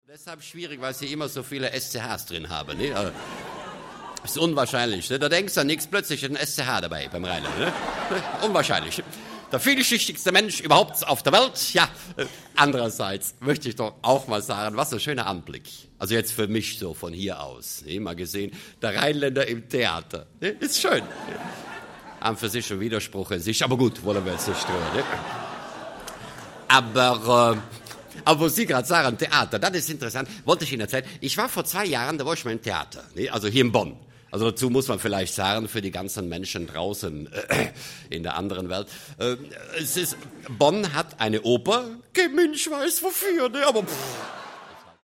Konrad Beikircher (Sprecher)